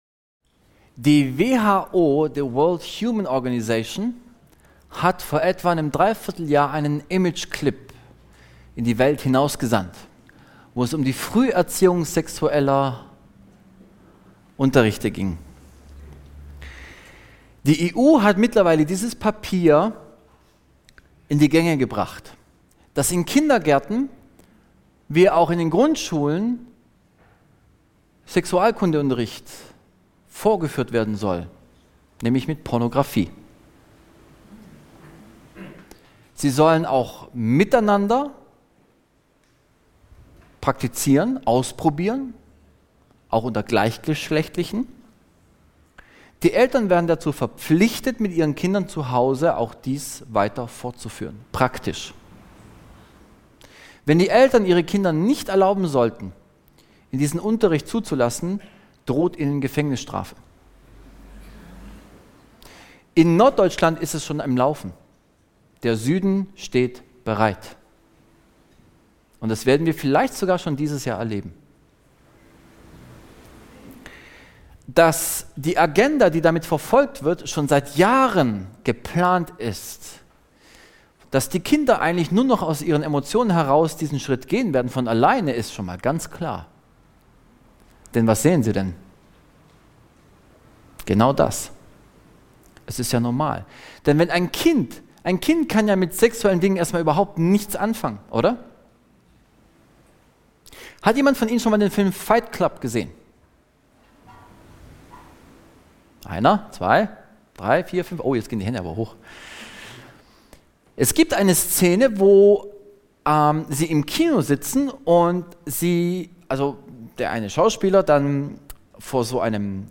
In diesem gut verständlichen Seminar werden aktuelle Erkenntnisse aus Medizin, Psychologie und Gottes Wort dargestellt.